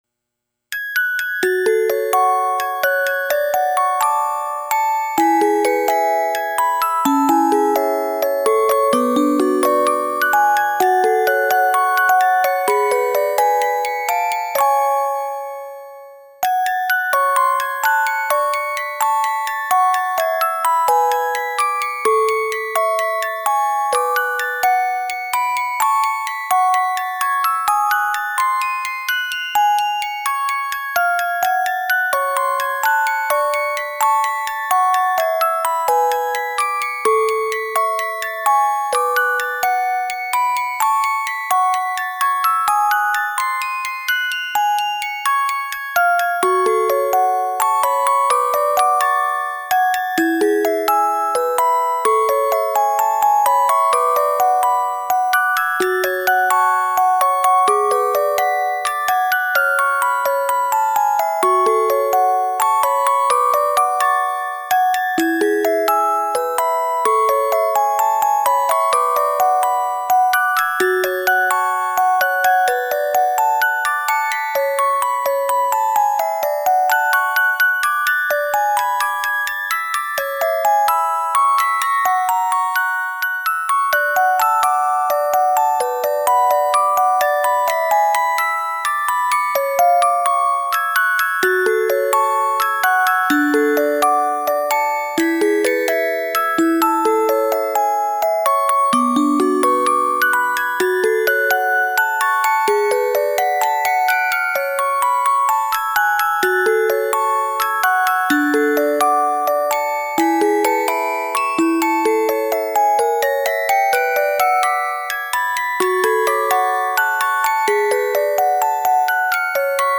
旧曲オルゴールCD